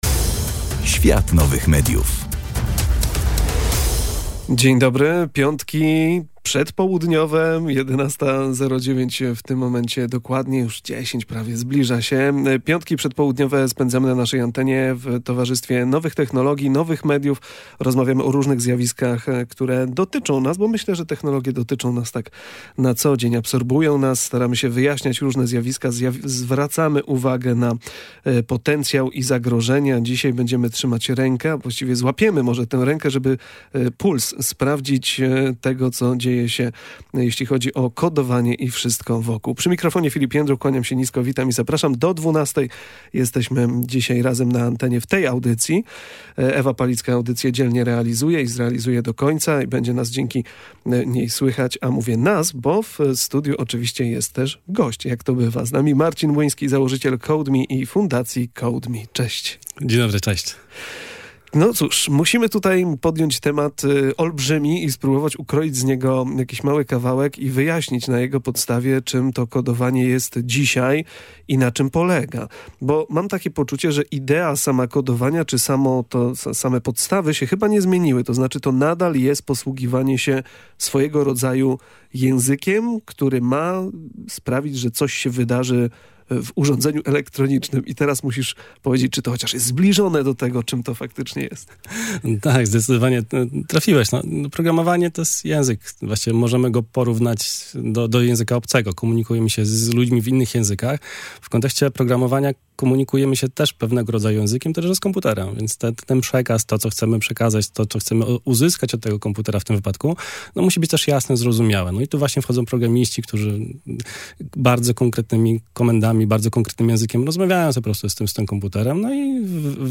W kolejnym wydaniu audycji „Świata Nowych Mediów” rozmawialiśmy o kodowaniu, wyzwaniach edukacyjnych, zmianach na rynku pracy i wszechobecnej sztucznej inteligencji.